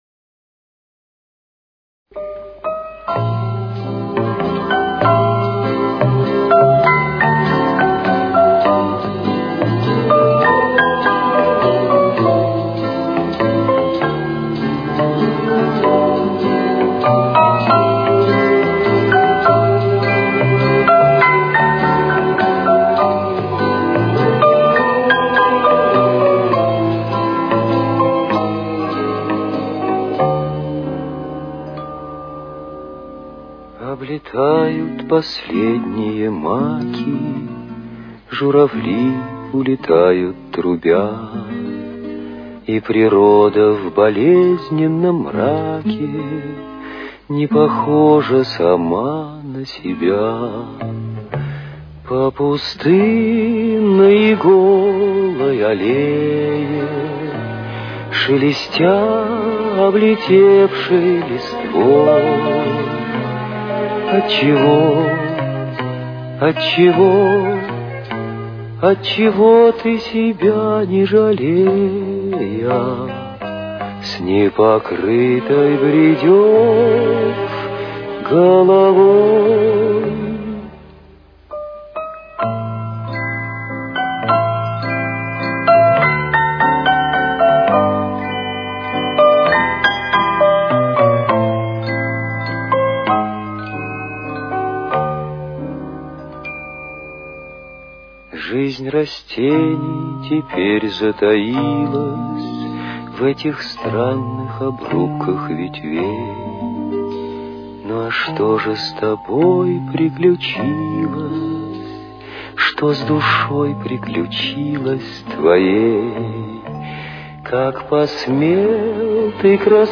Темп: 68.